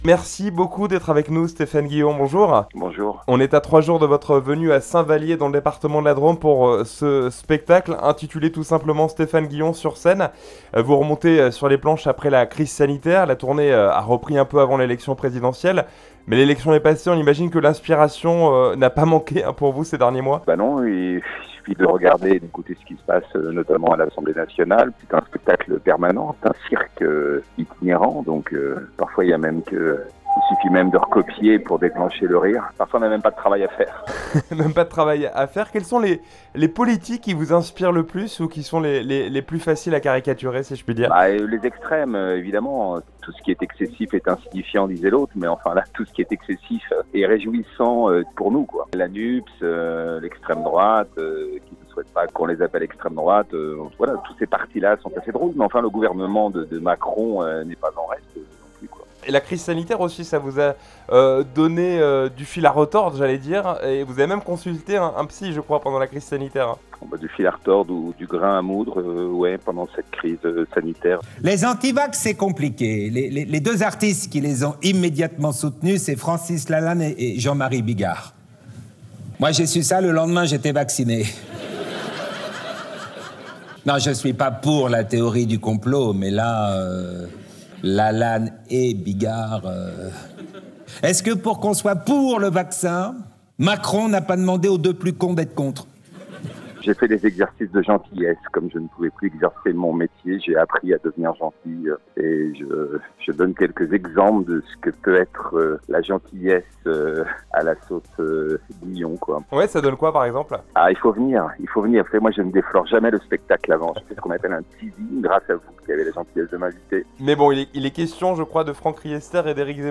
1011 INTERVIEW GUILLON